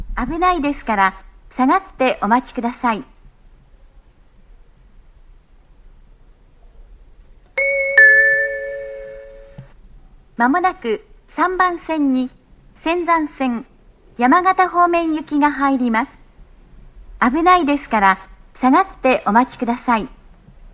アナウンスは上りが男声、下りが女性となっています。
下り接近放送(山形方面)